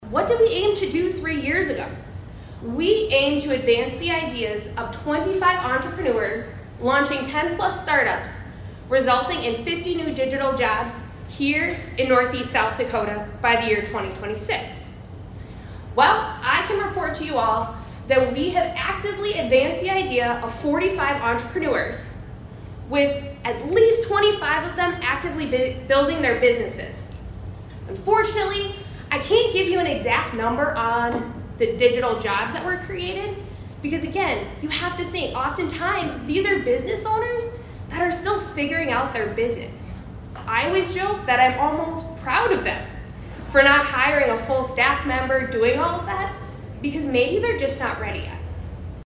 ABERDEEN, S.D.(HubCityRadio)- The Aberdeen Chamber of Commerce’s Chamber Connections Series continued Thursday at the K.O.Lee Public Library.